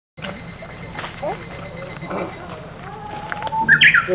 御岳山の山頂(?)に到着。
セミやウグイスが鳴いています。